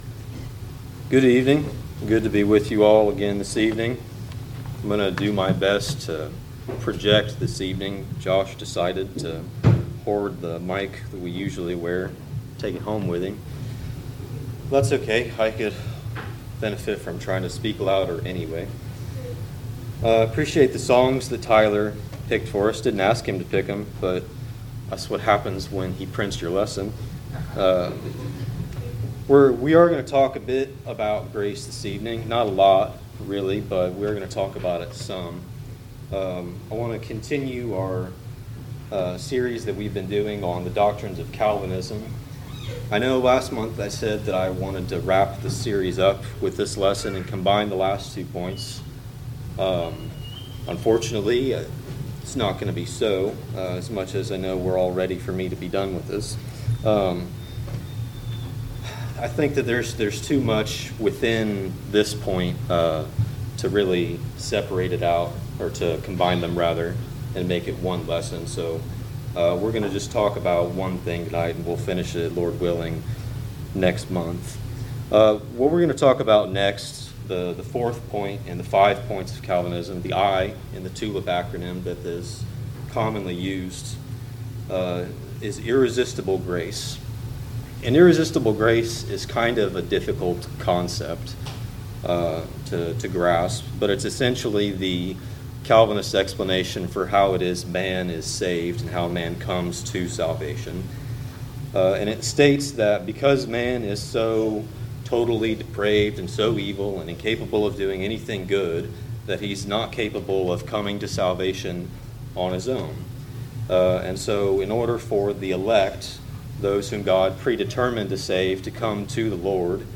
False Teachings Service Type: PM Topics